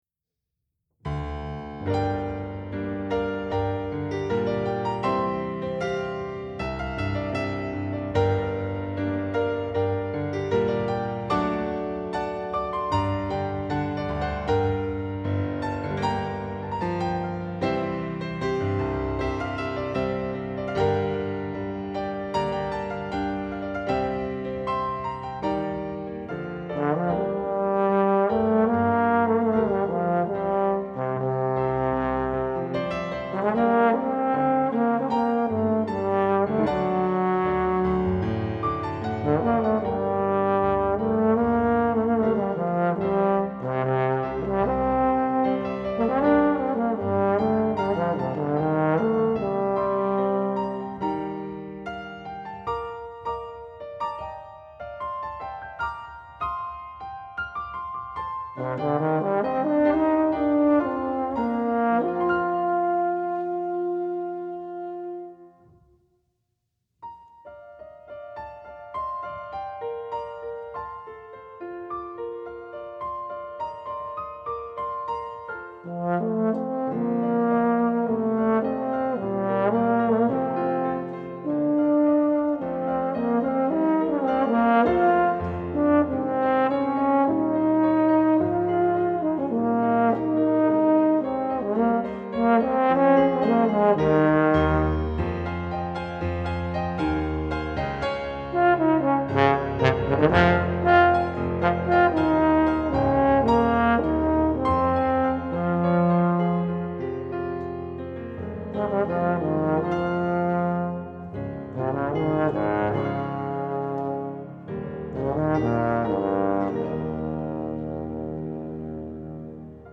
For Trombone Solo
Arranged by . with Piano: Bass or Tenor.